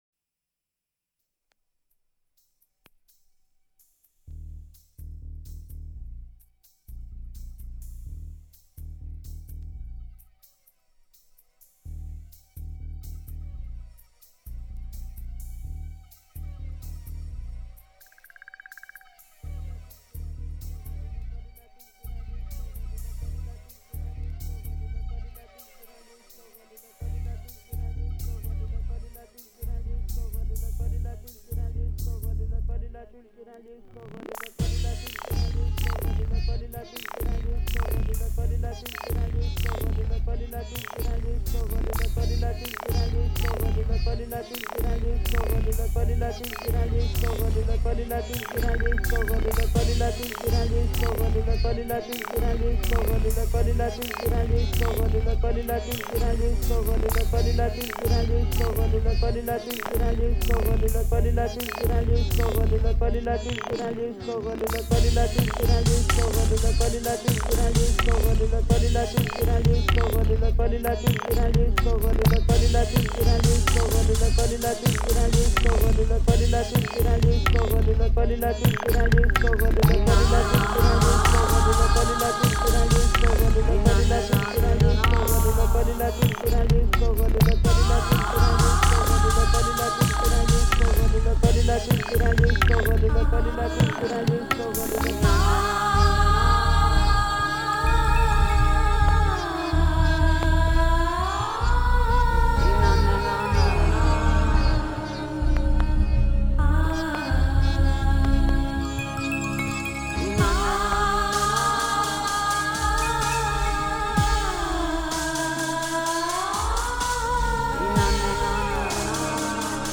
DJ SETS FROM PAST EVENTS
tech-house